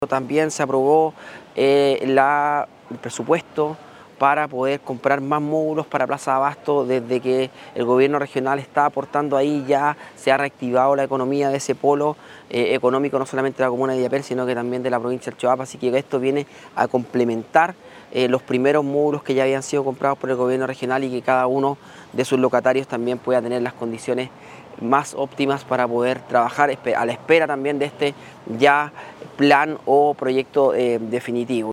Según detalló el gobernador Darwin Ibacache,
GOBERNADOR-REGIONAL-DARWIN-IBACACHE.mp3